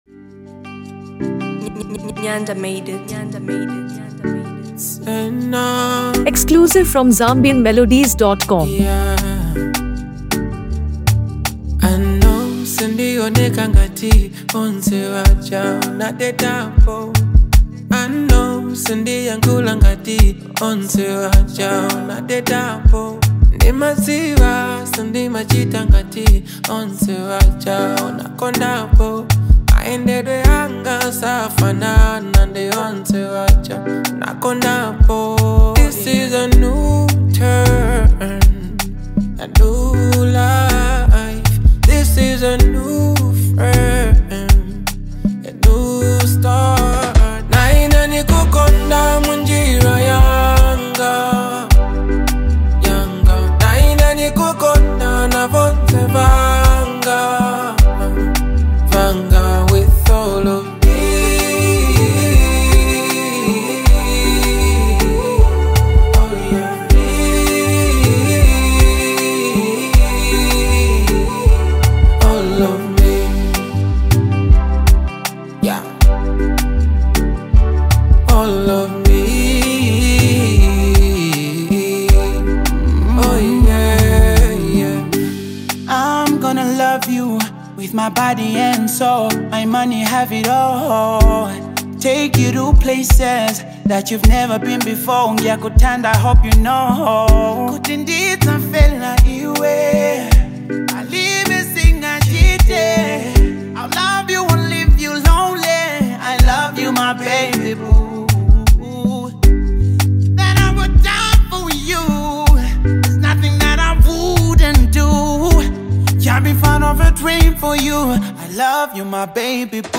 romantic and heartfelt track
Known for his seamless fusion of Afrobeat, R&B, and soul
a song filled with love, rhythm, and soul